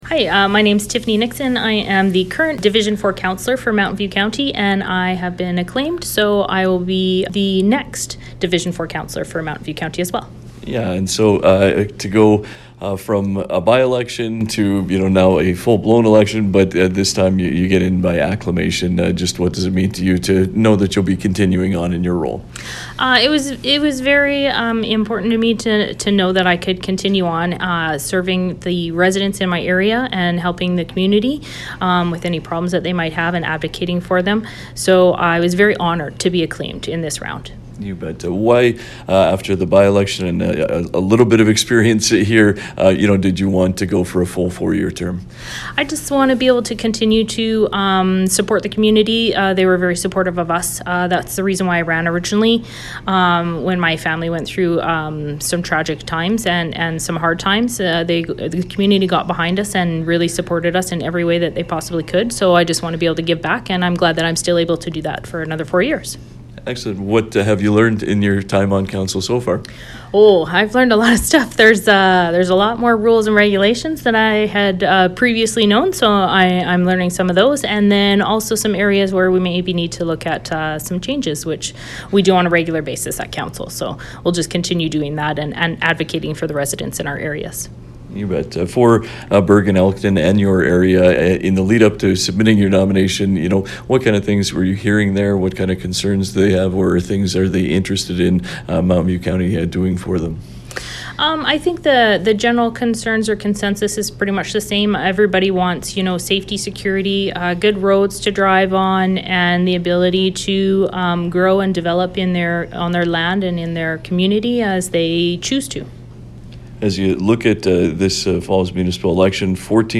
Listen to 96.5 The Ranch’s conversation with Tiffany Nixon.